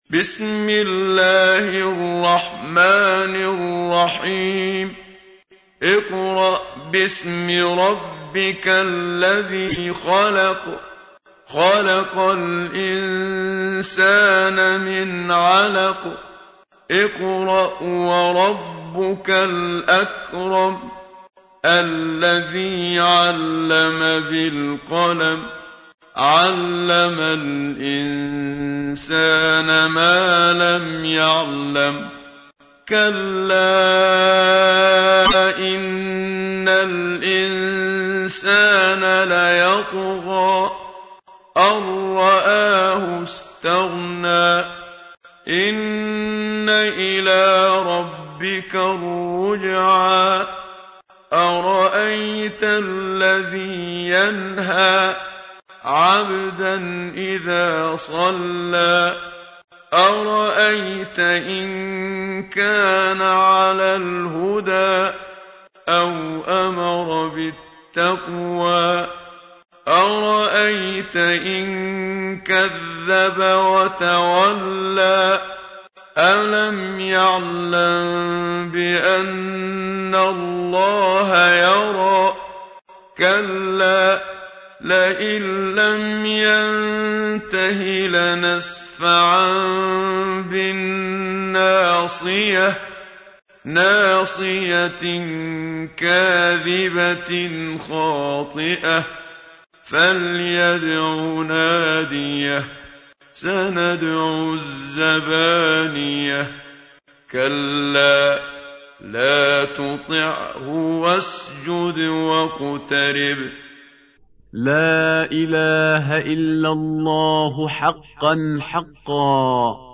تحميل : 96. سورة العلق / القارئ محمد صديق المنشاوي / القرآن الكريم / موقع يا حسين